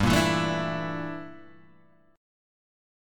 G Minor 6th Add 9th
Gm6add9 chord {3 1 2 2 3 0} chord